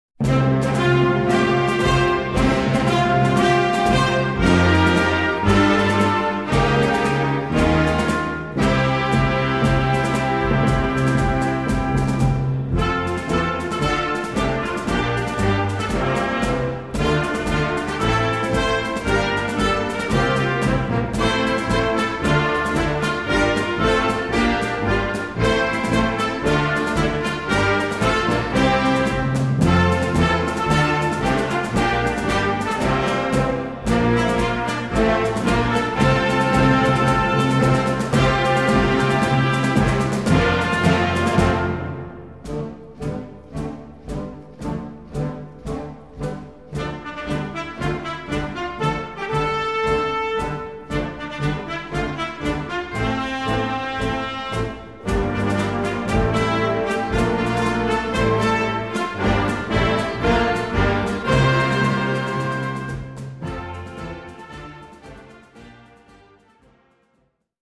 Répertoire pour Harmonie/fanfare - Concert Band